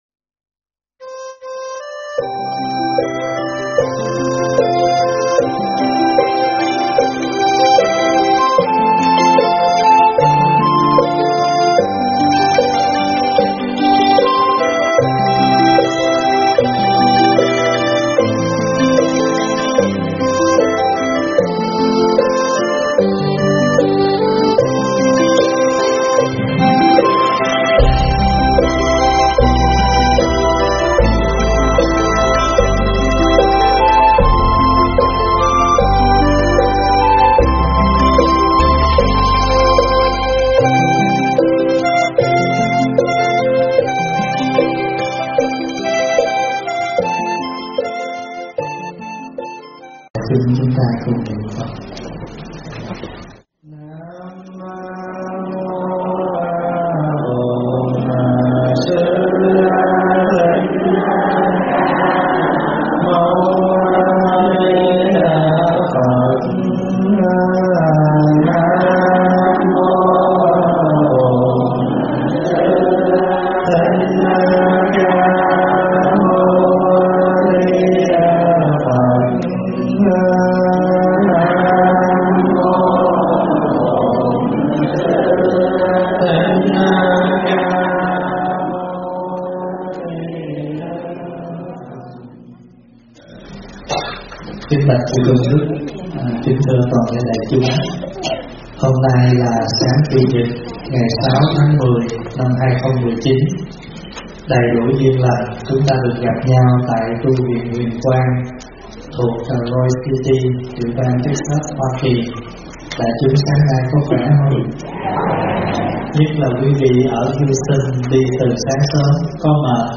Mp3 vấn đáp Cho Nhiều Phước Ít
Mp3 thuyết pháp Cho Nhiều Phước Ít